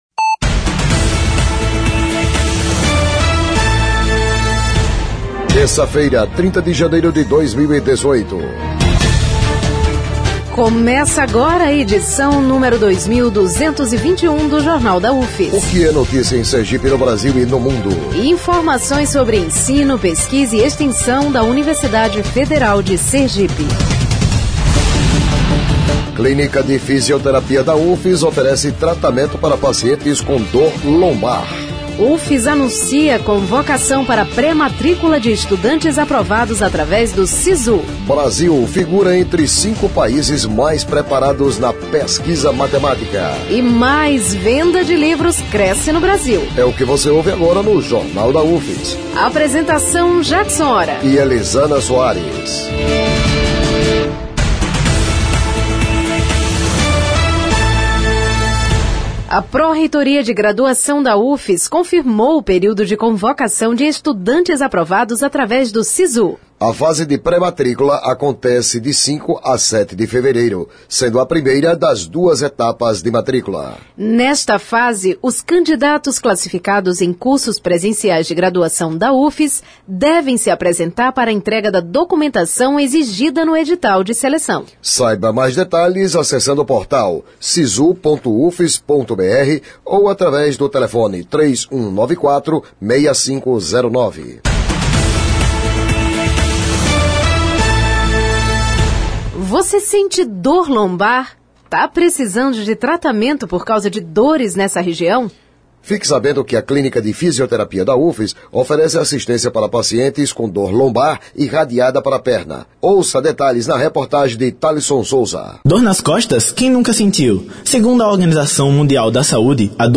O Jornal da UFS desta terça-feira, 30, destaca que Clínica de Fisioterapia da UFS está selecionando pacientes que sofrem de dor lombar irradiada para a perna para realizar tratamento fiisoterápico. A ideia surgiu através de uma dissertação de mestrado. O noticiário vai ao ar às 11h00 na Rádio UFS FM, com reprises às 17h00 e 22h00.